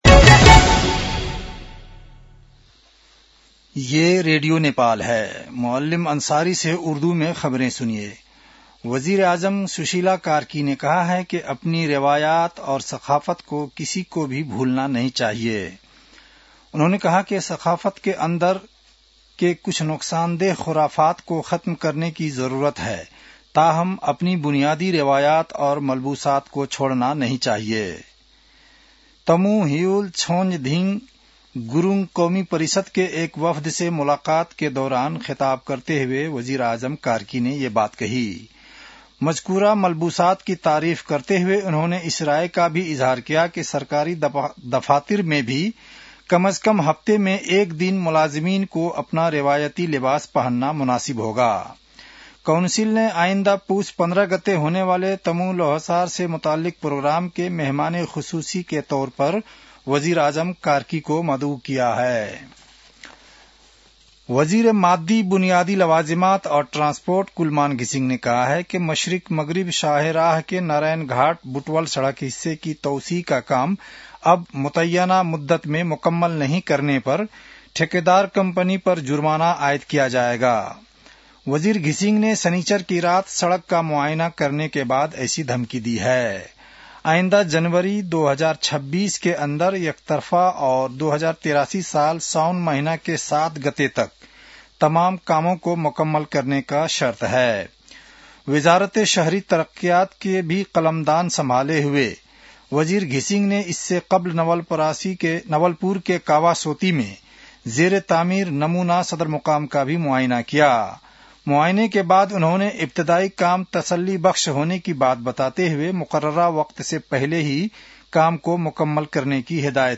An online outlet of Nepal's national radio broadcaster
उर्दु भाषामा समाचार : ६ पुष , २०८२
Urdu-news-9-06.mp3